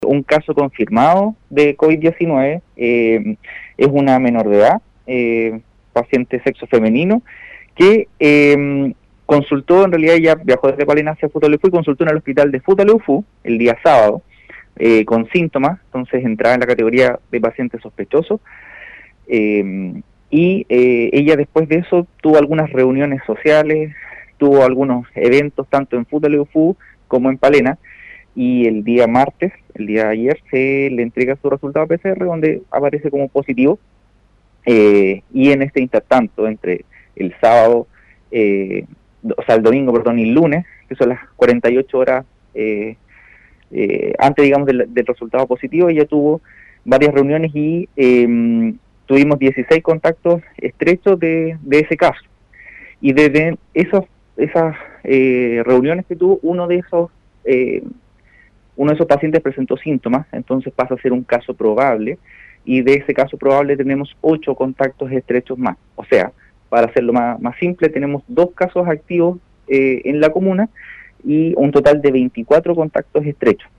en entrevista con radio Estrella del Mar de esa provincia